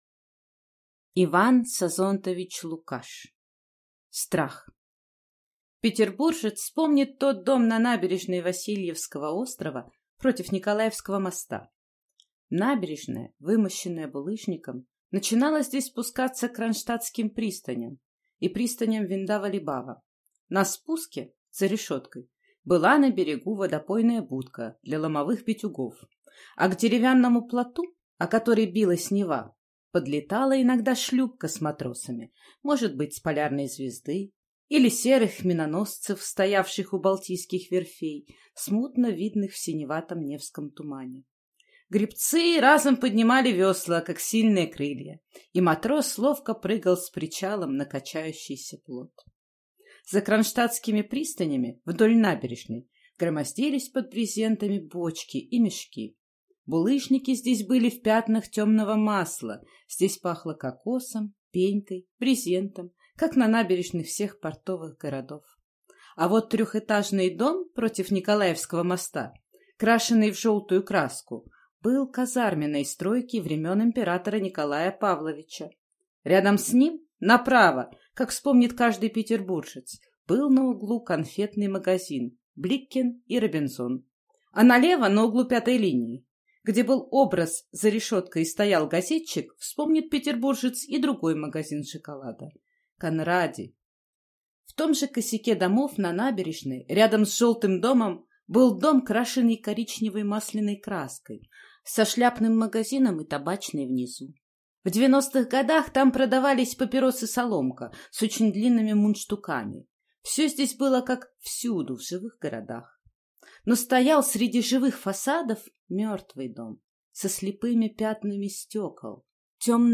Аудиокнига «Таноя».